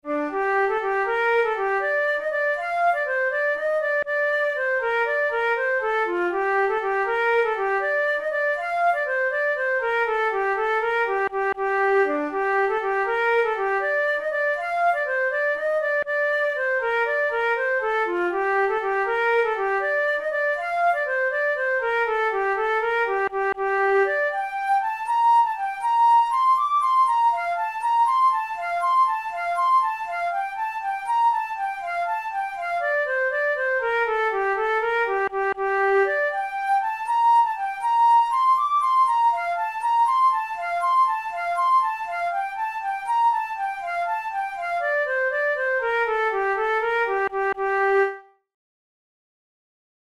InstrumentationFlute solo
KeyG minor
Time signature6/8
Tempo80 BPM
Jigs, Traditional/Folk
Traditional Irish jig